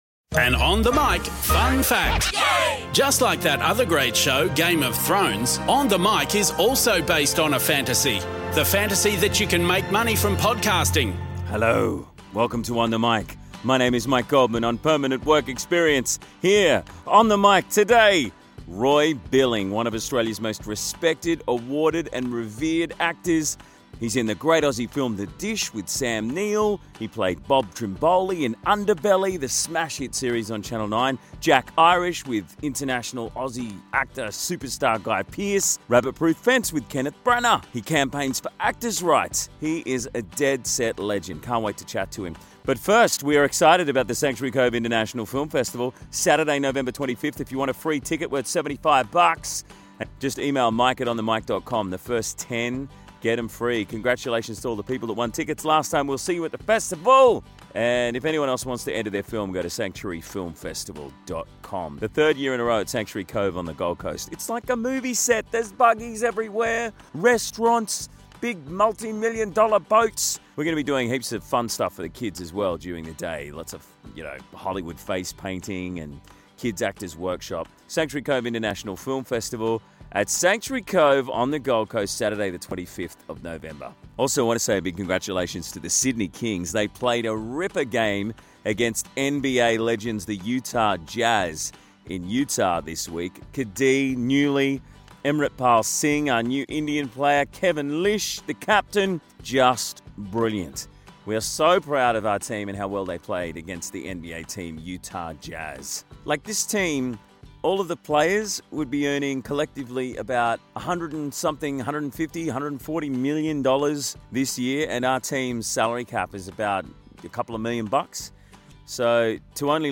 Live from North Bondi Surf Club thanks to Goldman Wines.